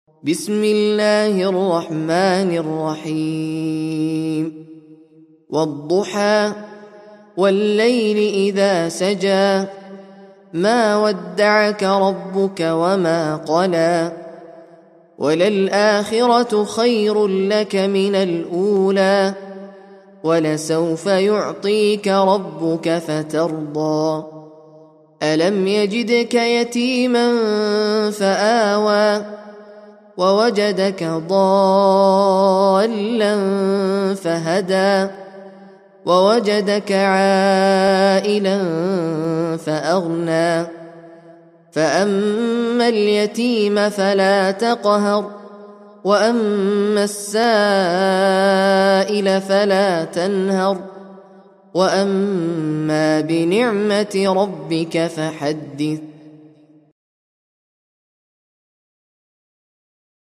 Sûrat Ad-Dhuha (The Forenoon) - Al-Mus'haf Al-Murattal